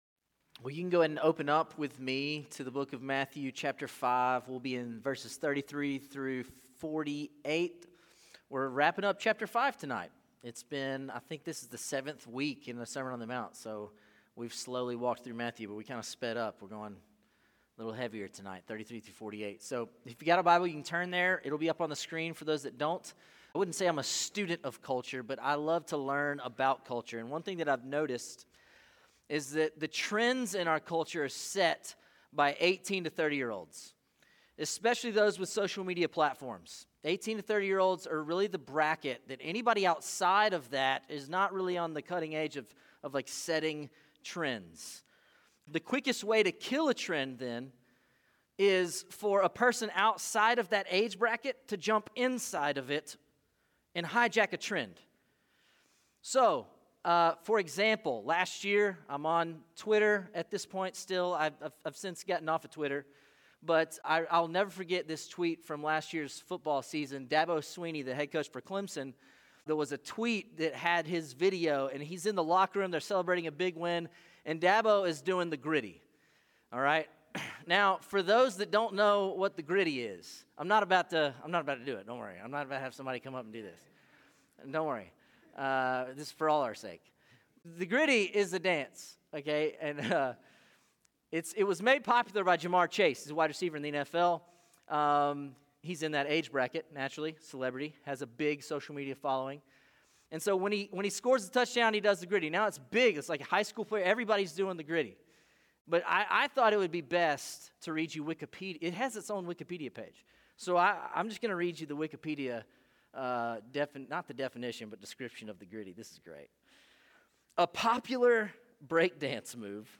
City View Church - Sermons